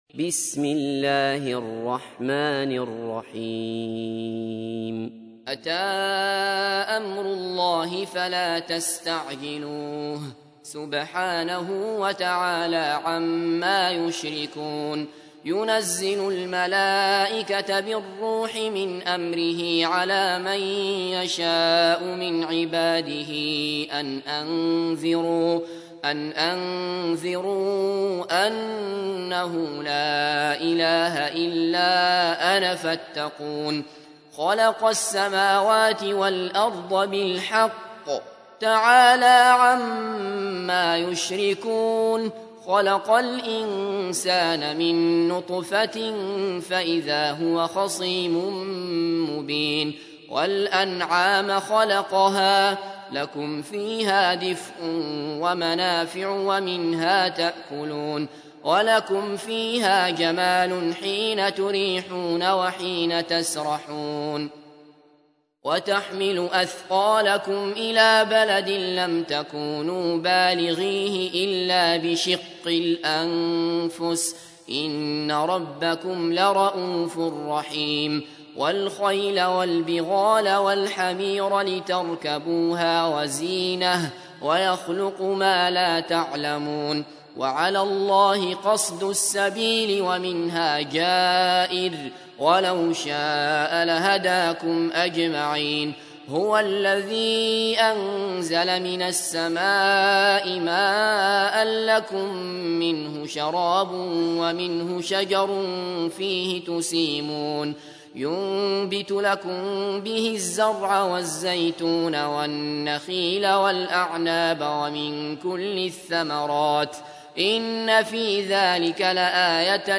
تحميل : 16. سورة النحل / القارئ عبد الله بصفر / القرآن الكريم / موقع يا حسين